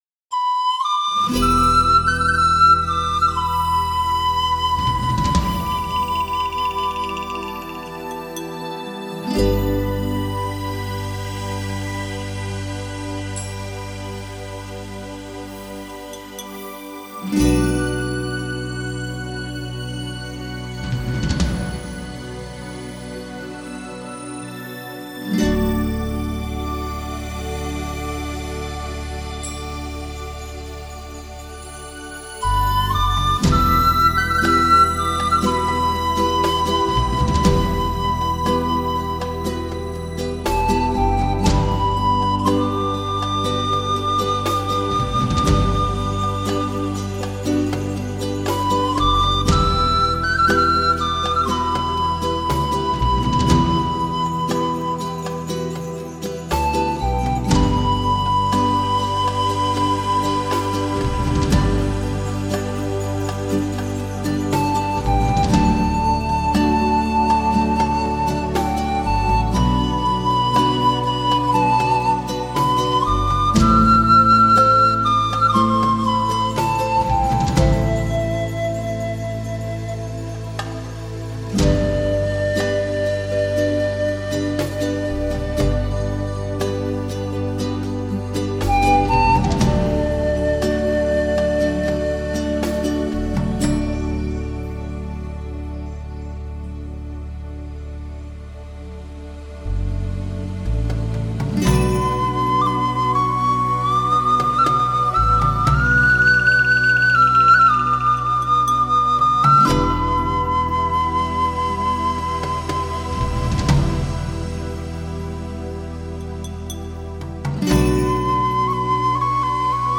乐迷们不再满足于欣赏一个区域、一个国家的音乐，而是醉心于探索、品尝来自万里之外，充满异国情调的新鲜音乐。